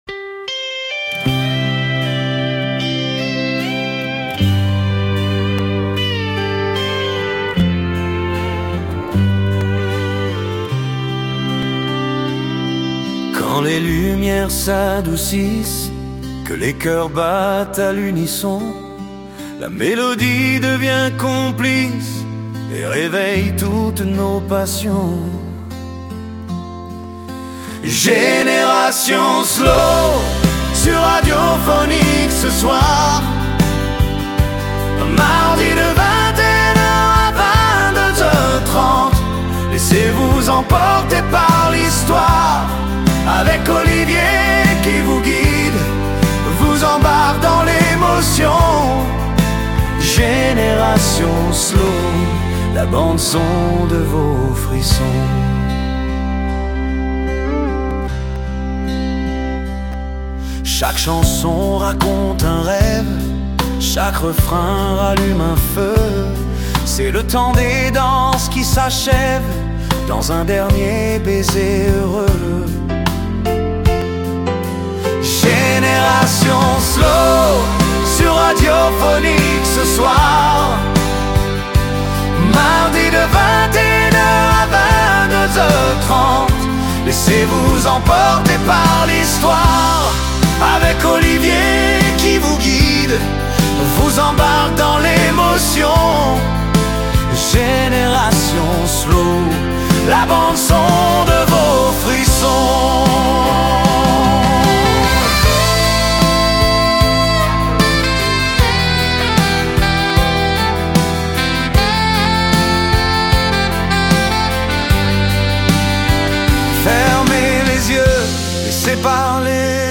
Génération Slow – Mardi 7 avril Il y a des soirs où le temps ralentit… où chaque note semble suspendue, où les émotions prennent toute la place.
Au fil de cette émission du mardi 7 avril, laissez-vous porter par une sélection de chansons douces, intenses et intemporelles… Des voix qui racontent, des mélodies qui apaisent, et ces instants rares où la musique devient un refuge.